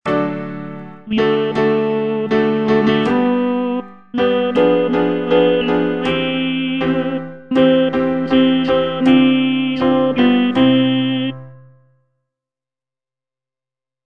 H. BERLIOZ - ROMÉO ET JULIETTE OP.17 Moderato - Bientôt de Roméo - Tenor (Voice with metronome) Ads stop: auto-stop